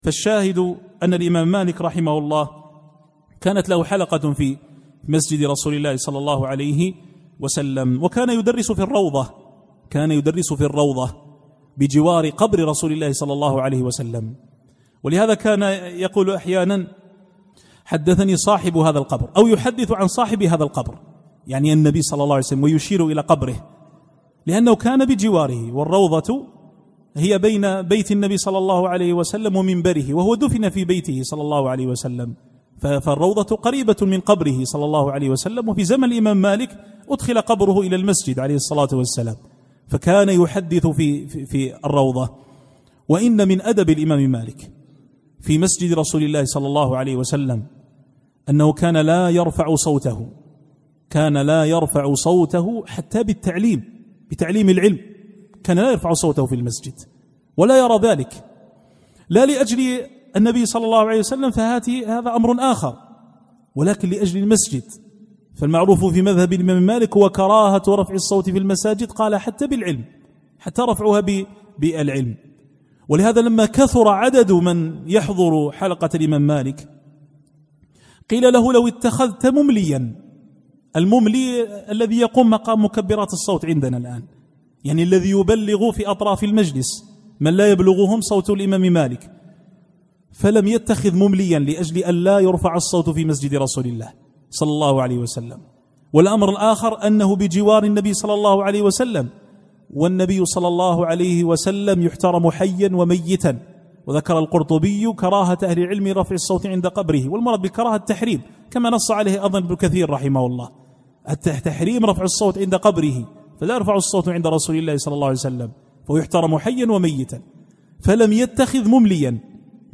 الإستماع - التحميل         الدرس الخامس